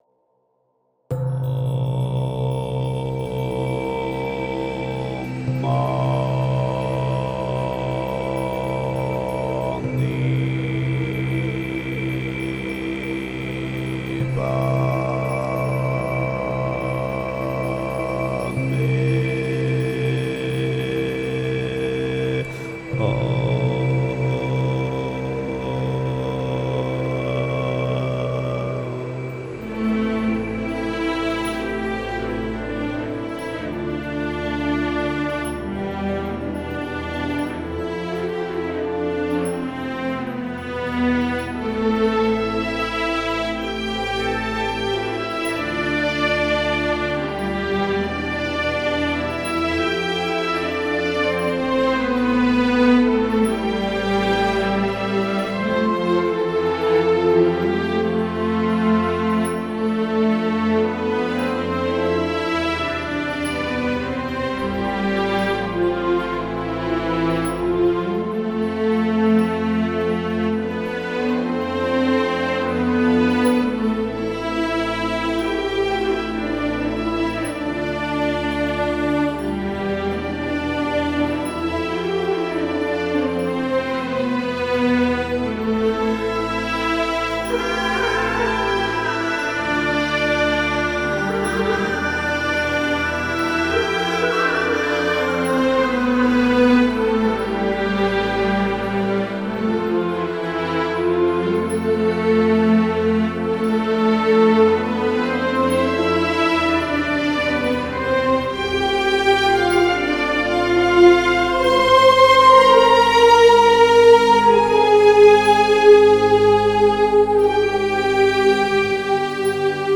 En tout cas le tout donne un bon petit relief qui nous entoure d'une douce aura apaisante.
Joli morceau j'aime bien l'harmonie qui est apaisante. Les voix apportent beaucoup ! Petit bémol vers 1/3 quand les cordes aigues arrivent, leur doublage à l'octave me plait moyennement
Joli morceau, très calme. Peut être un peu cliché Hollywoodien sur l’Himalaya dans la partie avec les cordes, mais c’est très zoli. A mon avis les cordes manquent un peu de legato. mais le reste est parfait. Belle performance de voix si elle est vraie au départ, dommage si c'est un sample. Ca cute sur la reverbe à la fin, dommage.